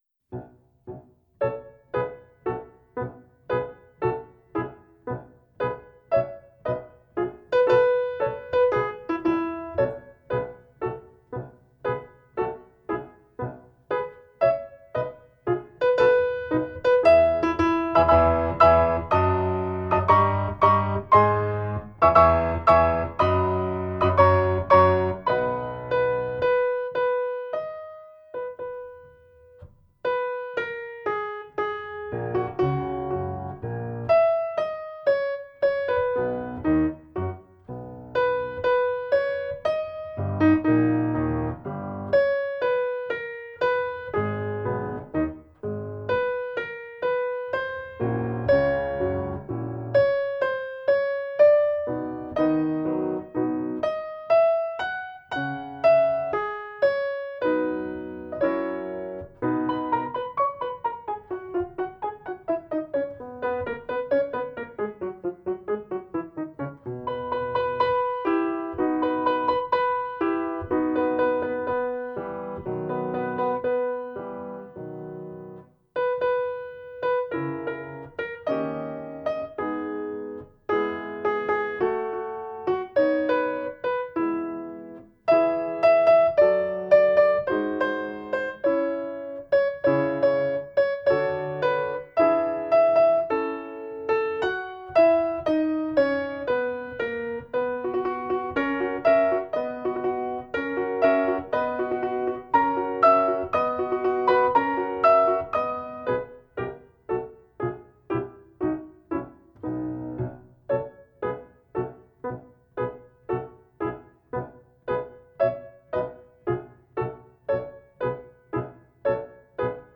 26_Saria possibile_base
26_Saria-possibile_base.mp3